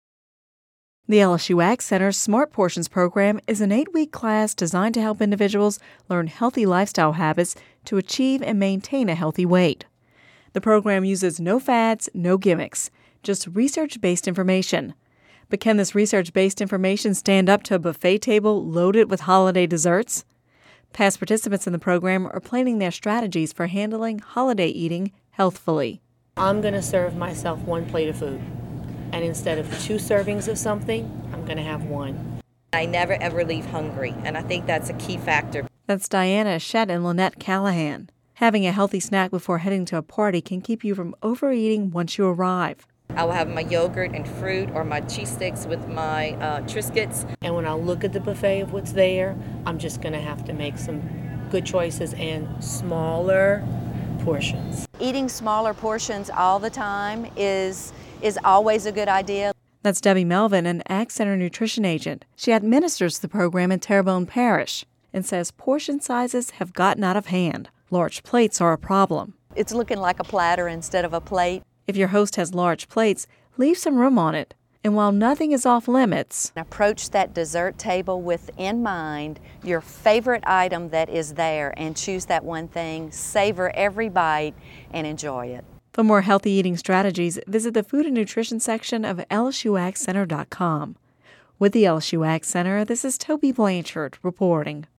(Radio News 12/06/10) The LSU AgCenter’s Smart Portions program offers eight weekly classes designed to help individuals learn good lifestyle habits to achieve and maintain a healthy weight.